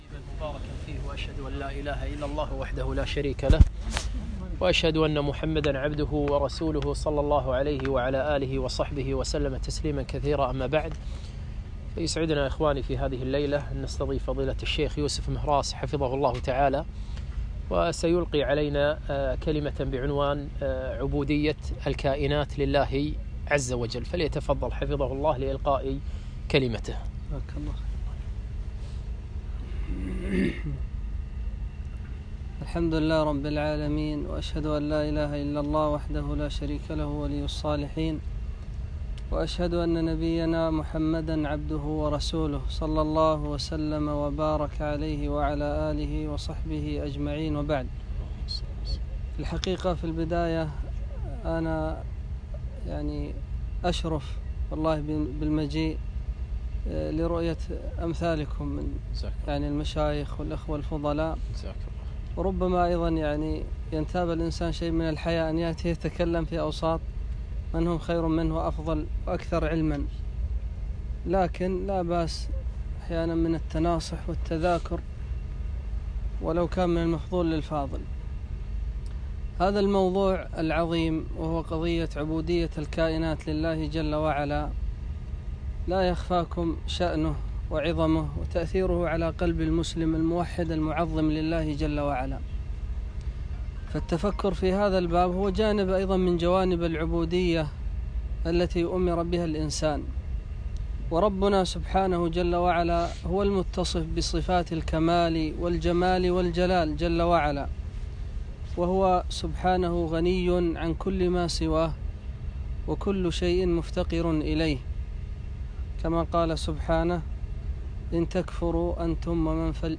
محاضرة - عبودية الكائنات لله عزوجل